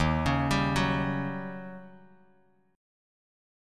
D#7#9 chord